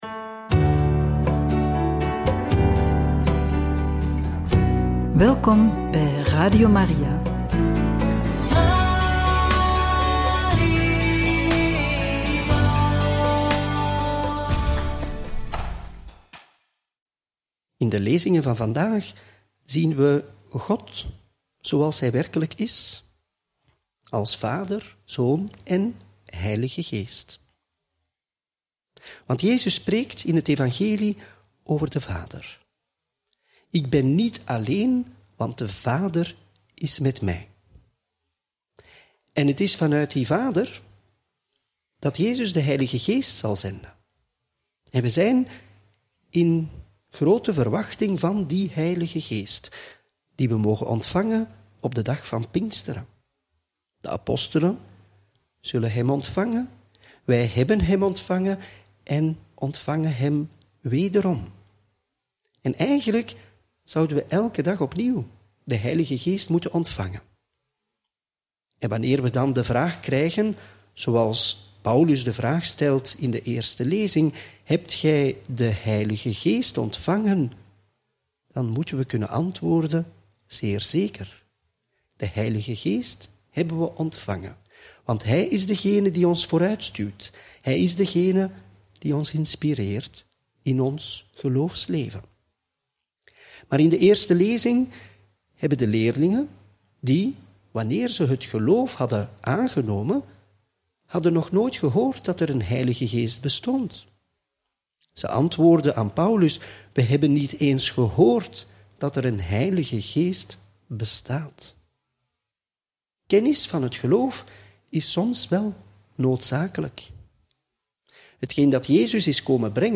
Homilie bij het Evangelie van maandag 2 juni 2025 – Joh 16, 29-33